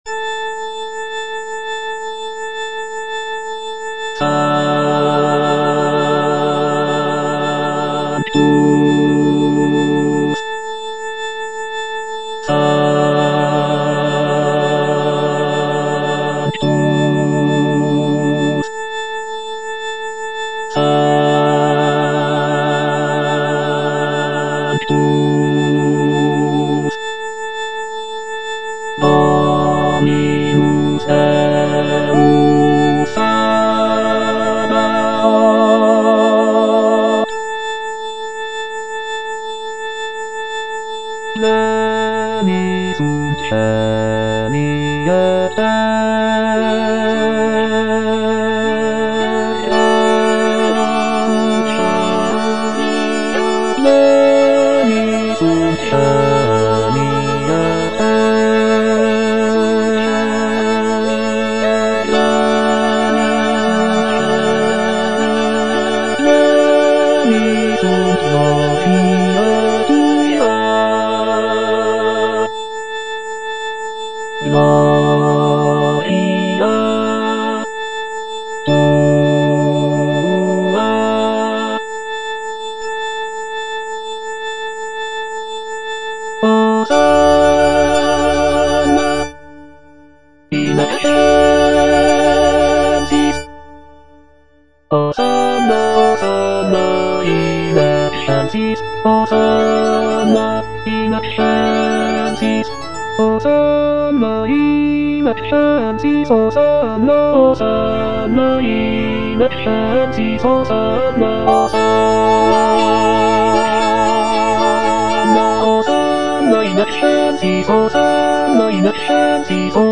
F. VON SUPPÈ - MISSA PRO DEFUNCTIS/REQUIEM Sanctus (bass II) (Emphasised voice and other voices) Ads stop: auto-stop